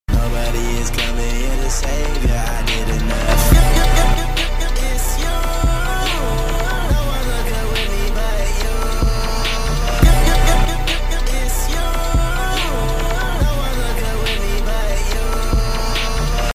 Bro the audio sounds messed up